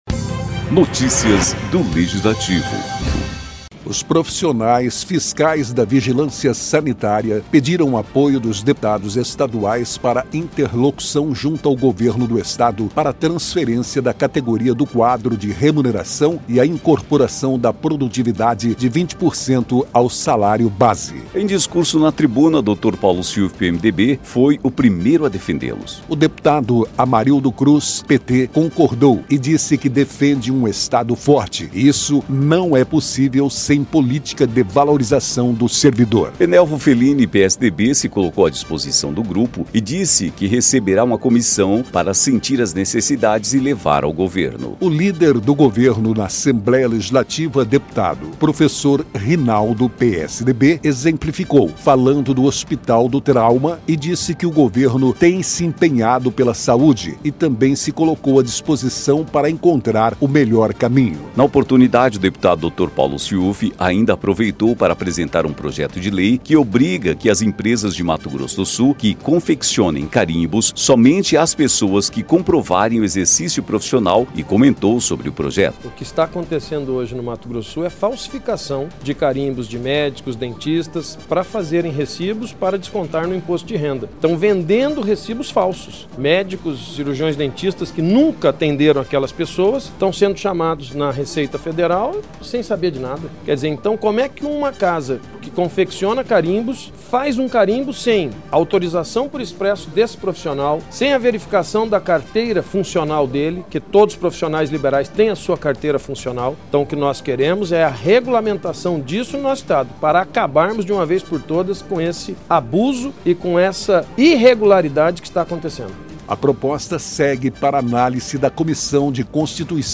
Em discurso na tribuna nesta quarta-feira (28/3), Dr. Paulo Siufi (PMDB) foi o primeiro a defendê-los.